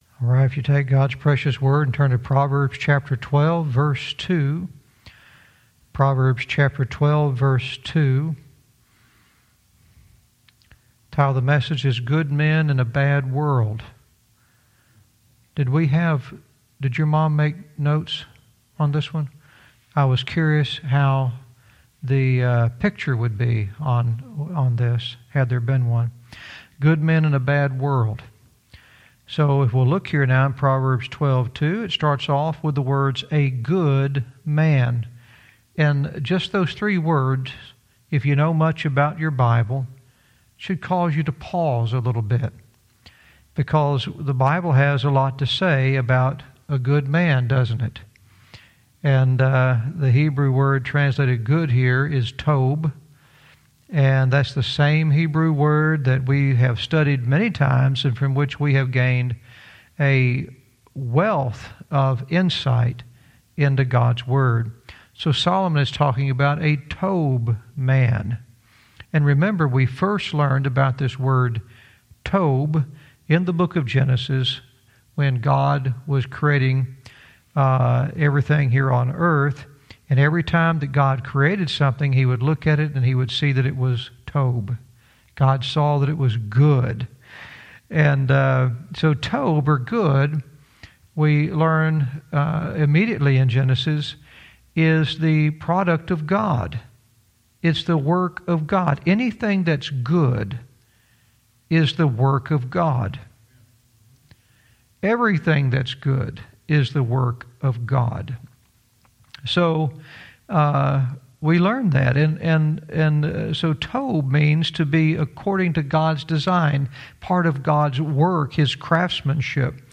Verse by verse teaching - Proverbs 12:2-3 "Good Men in a Bad World"